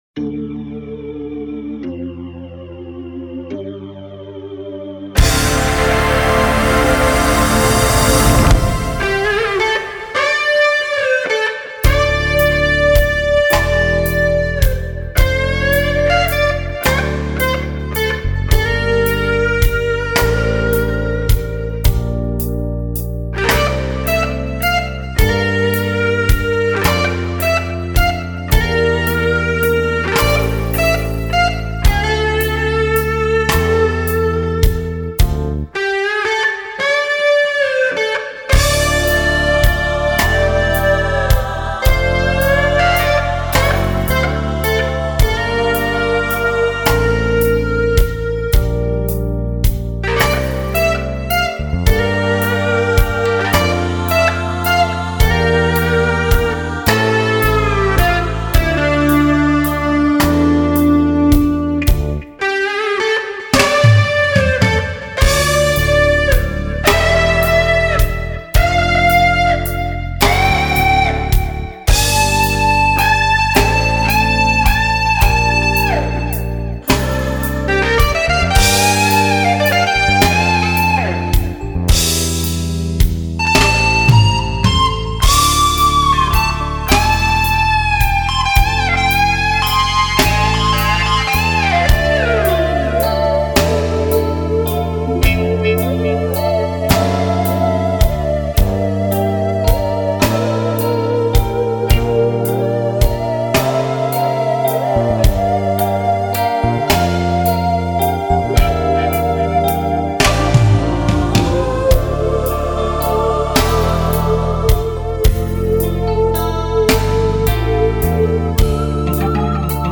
Música popular: instrumental y jazz